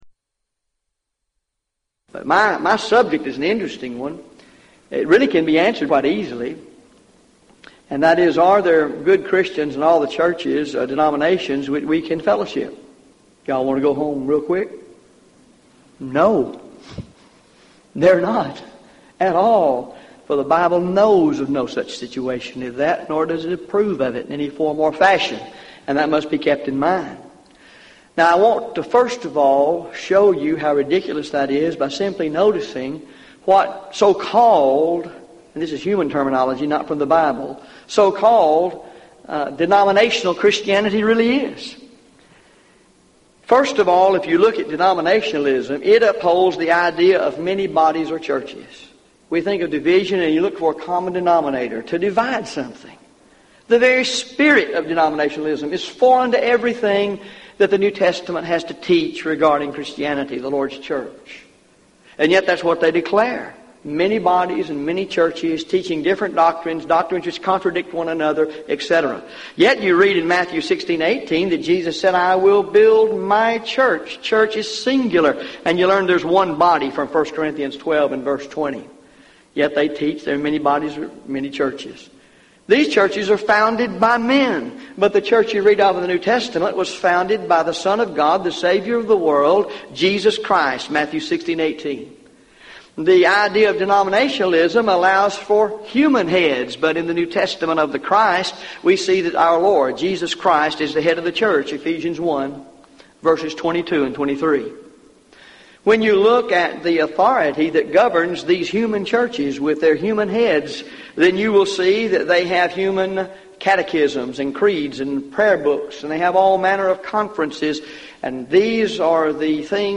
Event: 1999 Gulf Coast Lectures